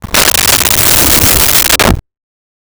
Creature Growl 01
Creature Growl 01.wav